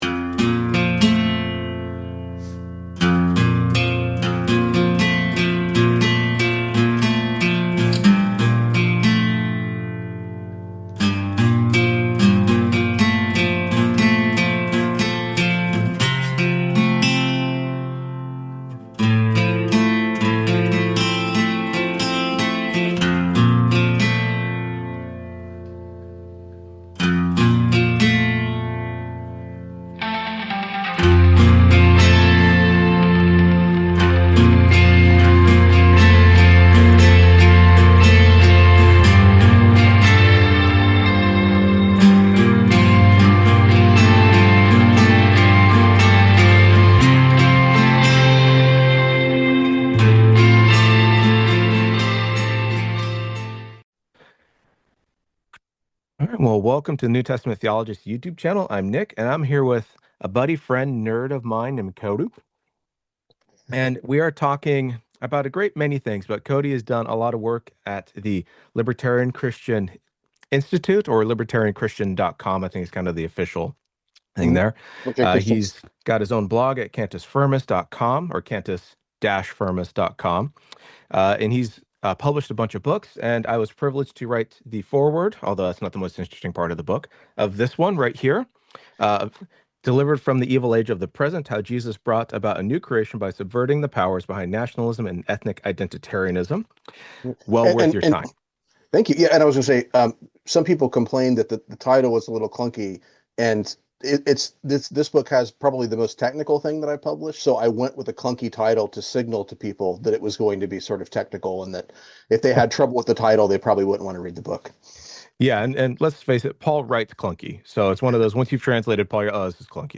Apocalyptic Libertarianism?: A Conversation about Paul and Power (New Testament Theologist)